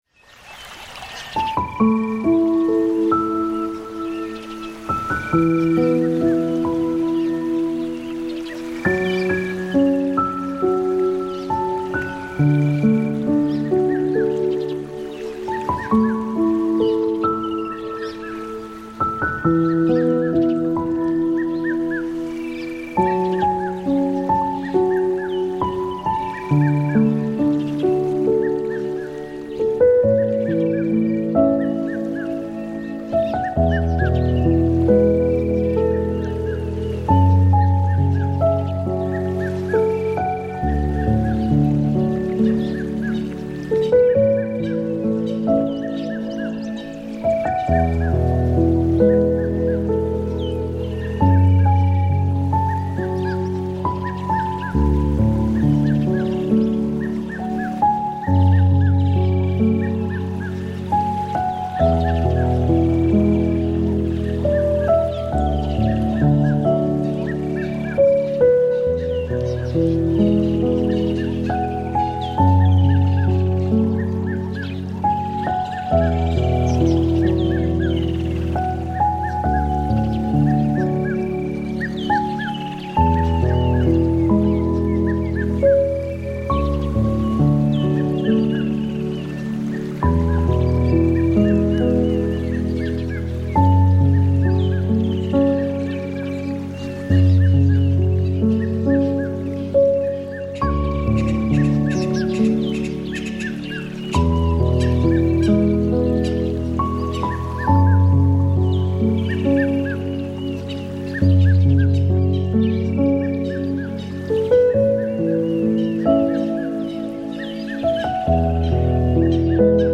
Naturgeräusche